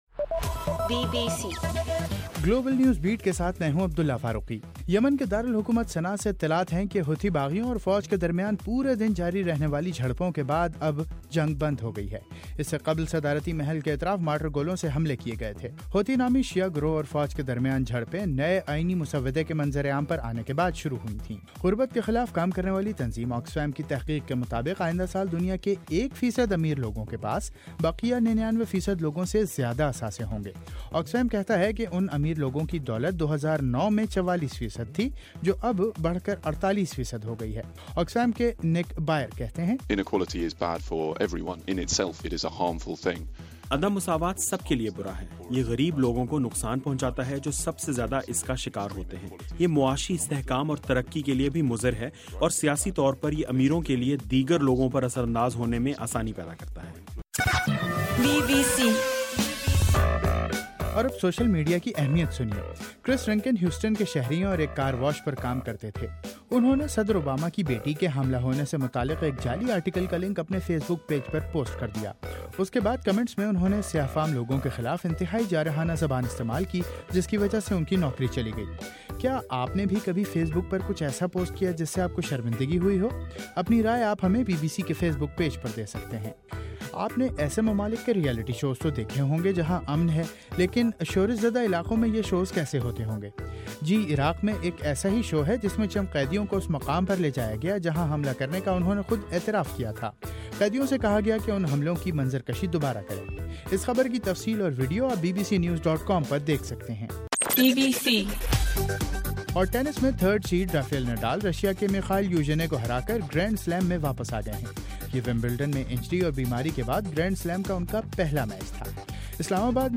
جنوری 19: رات 11 بجے کا گلوبل نیوز بیٹ بُلیٹن